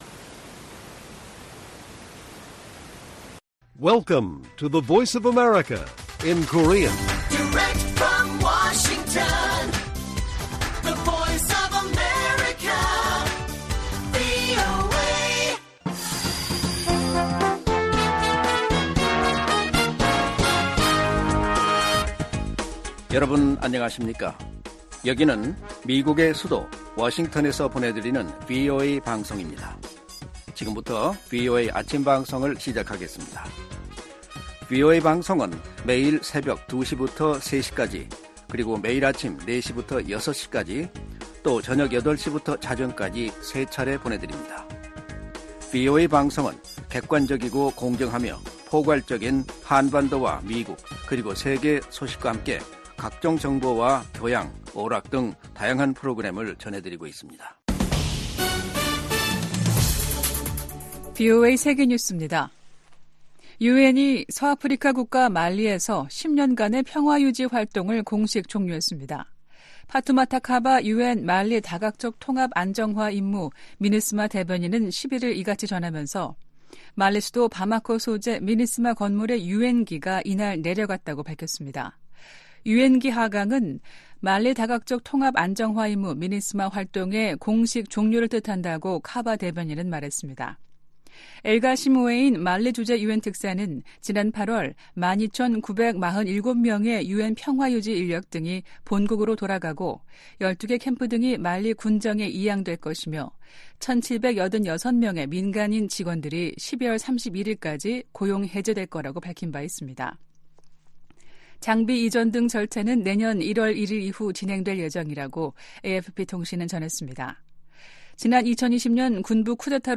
세계 뉴스와 함께 미국의 모든 것을 소개하는 '생방송 여기는 워싱턴입니다', 2023년 12월 12일 아침 방송입니다. '지구촌 오늘'에서는 중국과 필리핀 선박들이 남중국해에서 다시 충돌한 소식 전해드리고, '아메리카 나우'에서는 최근 '독재자' 발언으로 논란을 일으켰던 도널드 트럼프 전 대통령이 이에 관해 언급한 이야기 살펴보겠습니다.